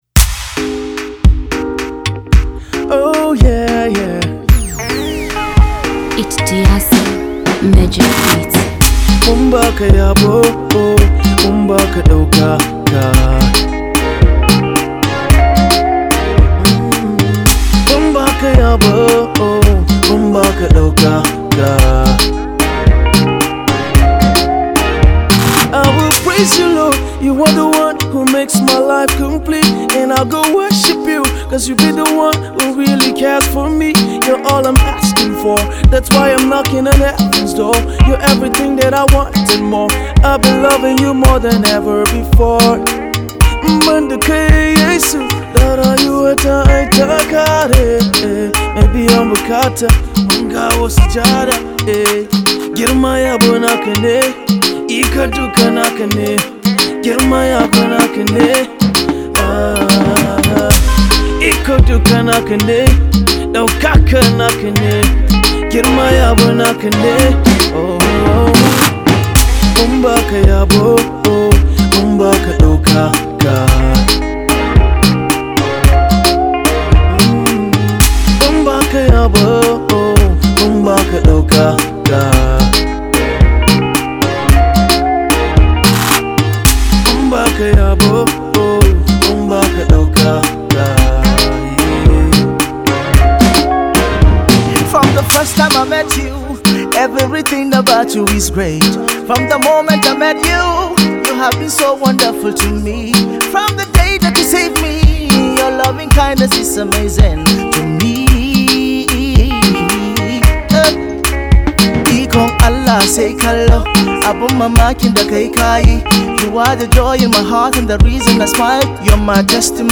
gospel artist and songwriter
mind soothing song